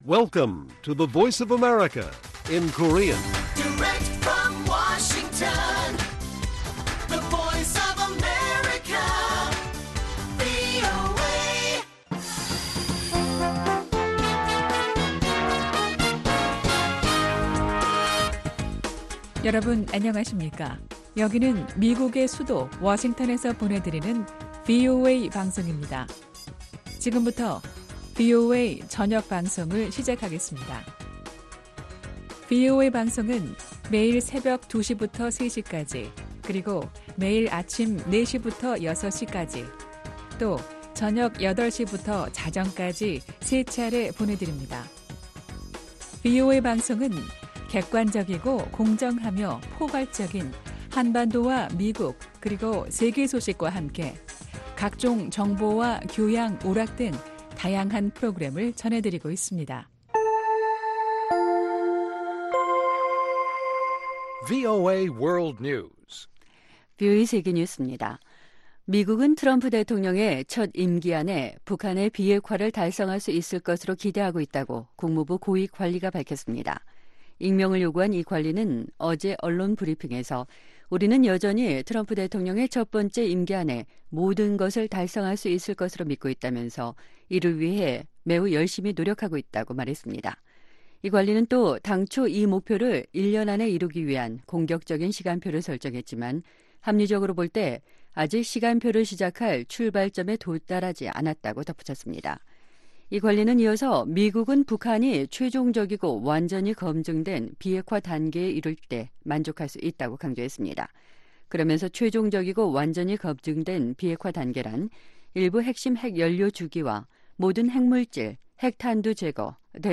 VOA 한국어 간판 뉴스 프로그램 '뉴스 투데이', 2019년 3월 8일 1부 방송입니다. 도널드 트럼프 미국 대통령은 북한의 장거리 미사일관련 시설에서 새로운 움직임이 포착된 데 대해 약간 실망했다고 말했습니다. 트럼프 대통령은 북한이 ‘큰 그림’을 살펴볼 준비가 되면 합의가 가능하다고 보고 있다고, 존 볼튼 백악관 국가안보회의 보좌관이 밝혔습니다.